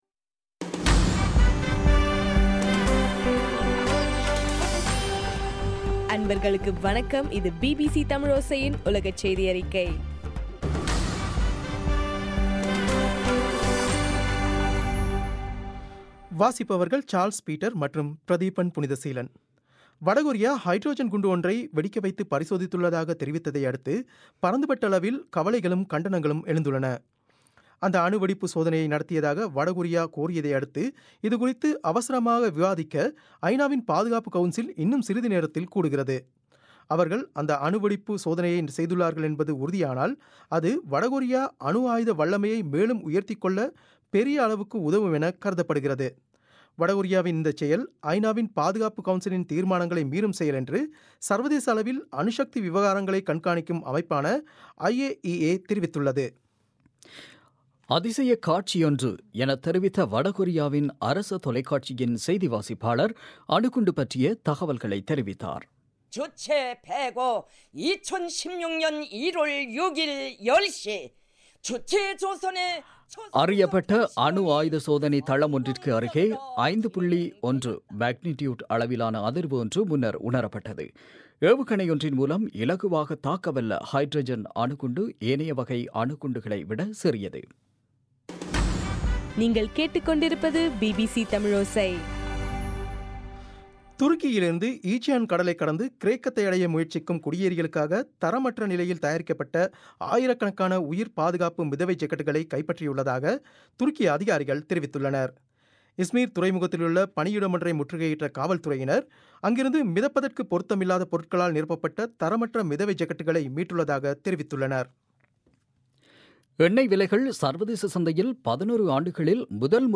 ஜனவரி 6, 2016 பிபிசி தமிழோசையின் உலகச் செய்திகள்